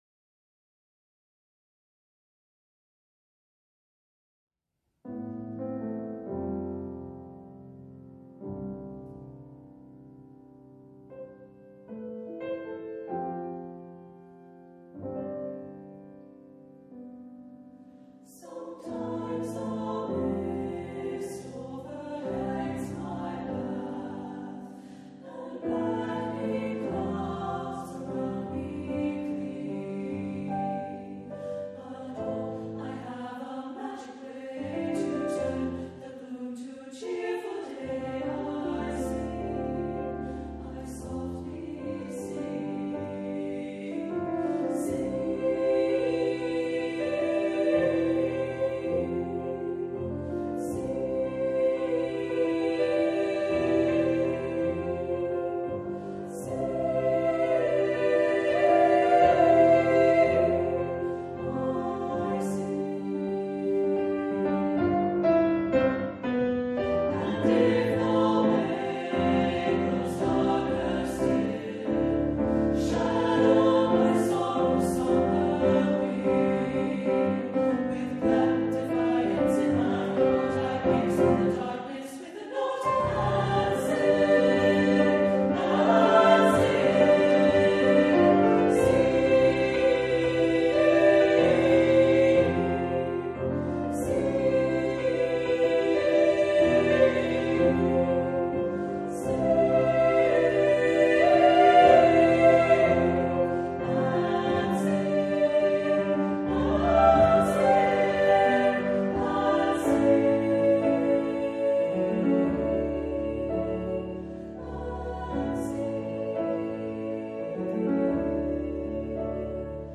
Voicing: "SSA"